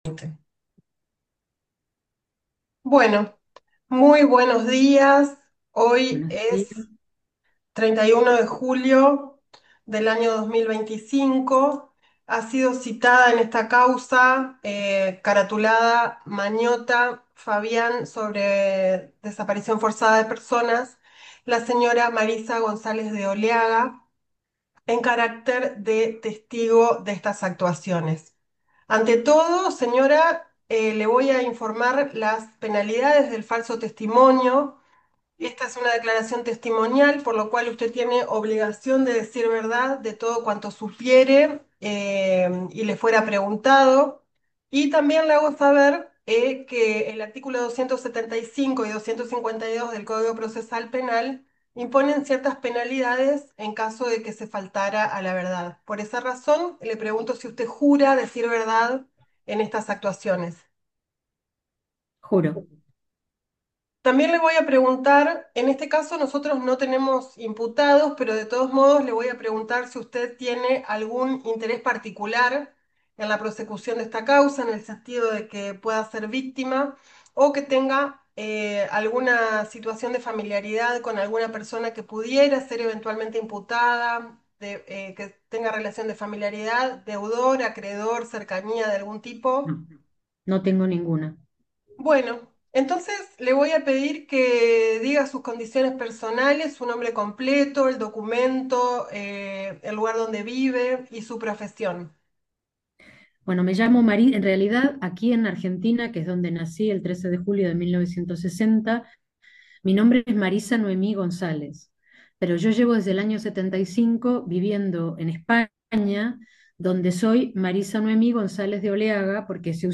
Juicio de los Vuelos de la muerte de Entrerríos - Audios - Territorios de la Memoria | TEMUCO